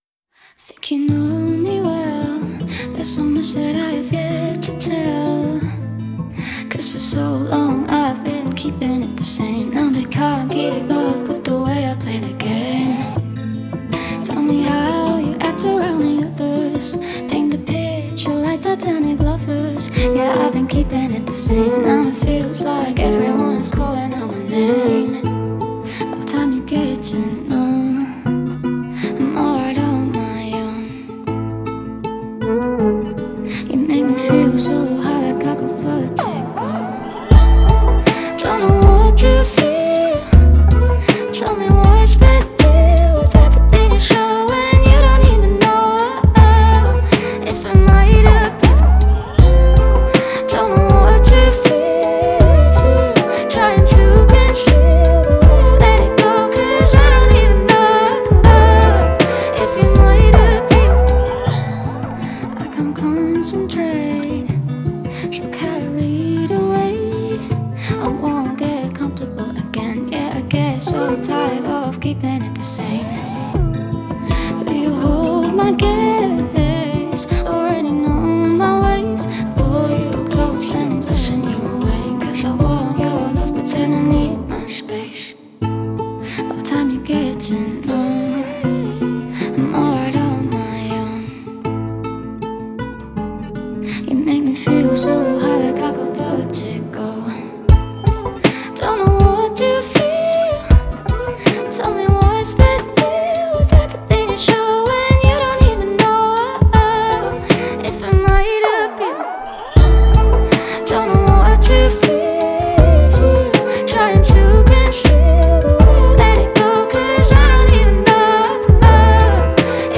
сочетающая элементы поп и электронной музыки.